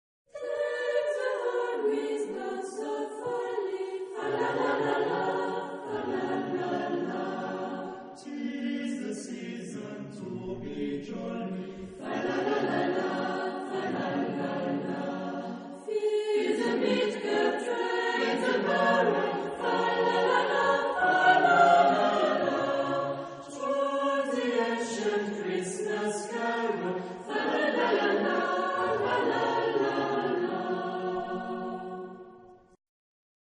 Genre-Style-Form: Choir ; Christmas song
Type of Choir: SATB  (4 mixed voices )
Source of text: traditional Welsh carol